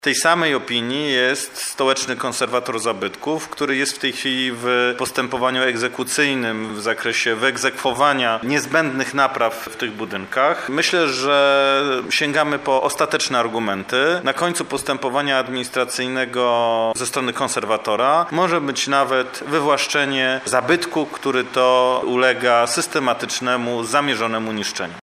Sprawą zajmuje się również stołeczny konserwator zabytków – dodaje Krzysztof Strzałkowski.